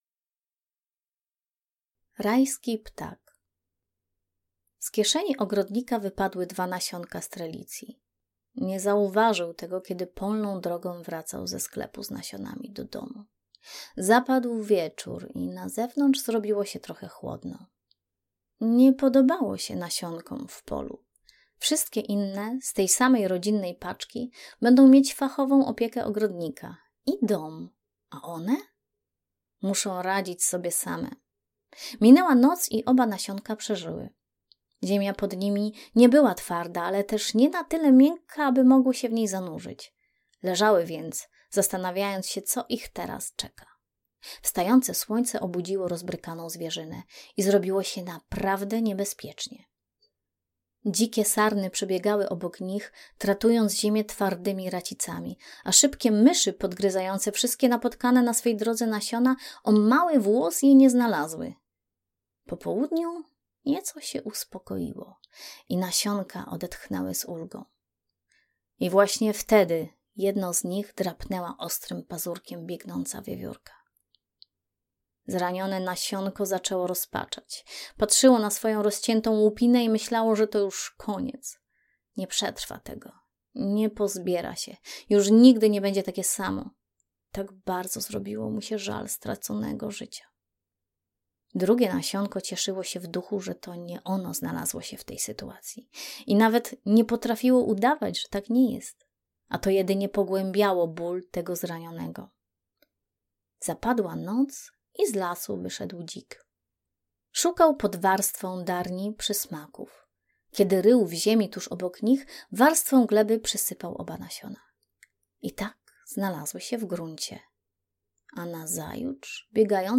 Opowieści pisane sercem 2 - audiobook